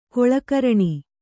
♪ koḷakaraṇi